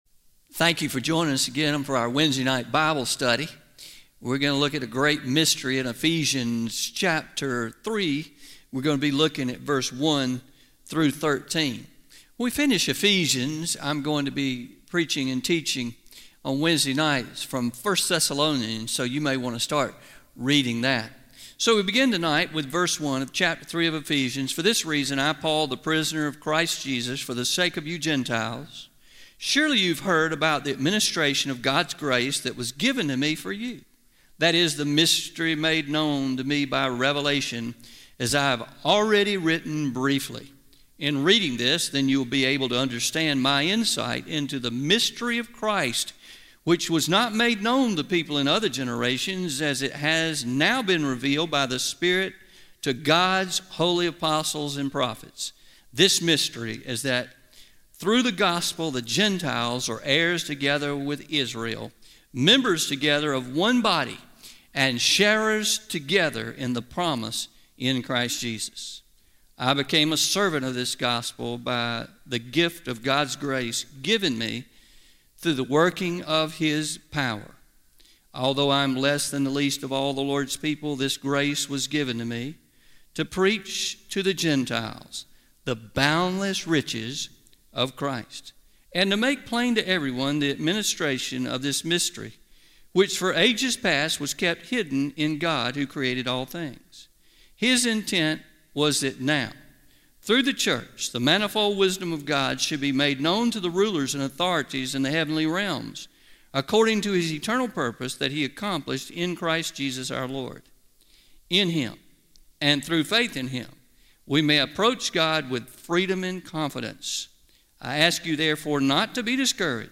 09-22-21AM Sermon – A Wonderful Mystery